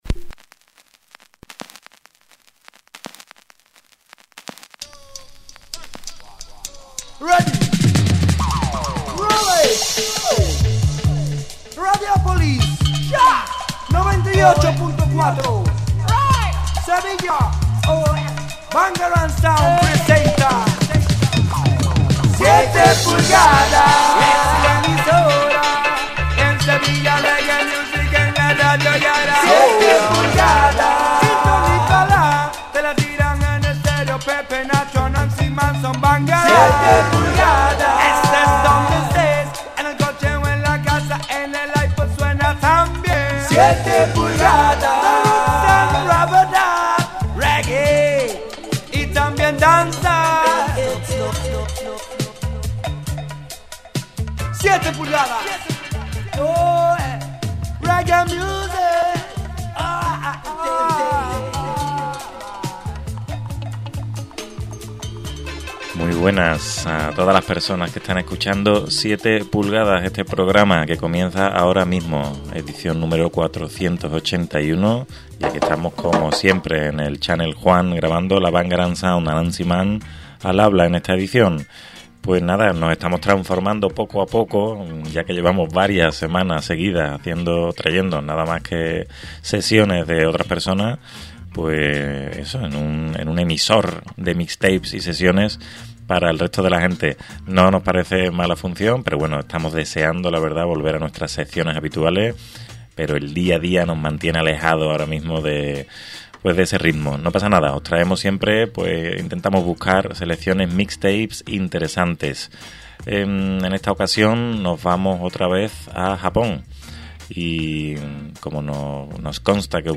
grabado en Channel Juan Studio